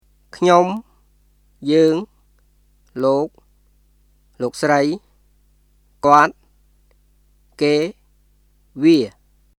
[クニョム、ジューン、ローク、ローク・スライ、コアット、ケー、ヴィア　kʰɲom,　yə̀ːŋ,　lòːk,　lòːk srəi,　kɔət,　kèː,　viˑə]